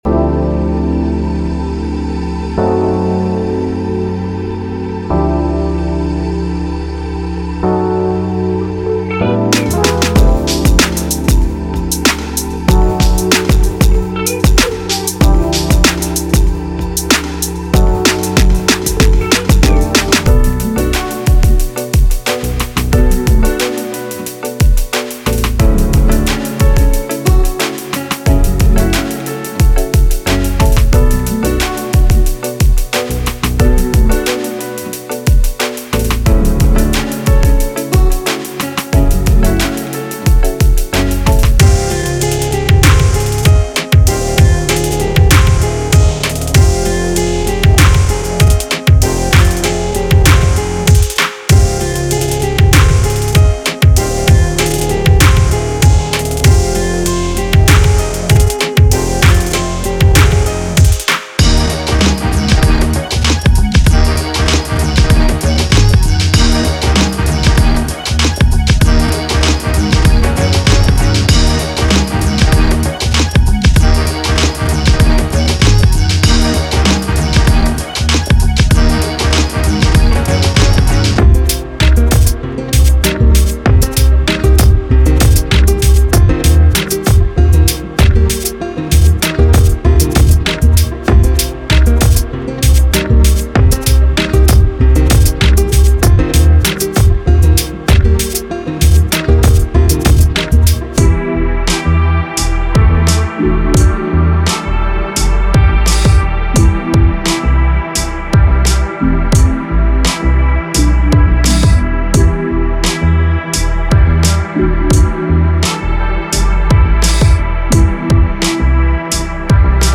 Hip-Hop / R&B Pop Trap